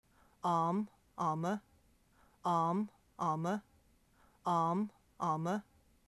The recordings were gained from native speakers from the individual areas as part of the project Samples of Spoken Irish.
context northern western southern sample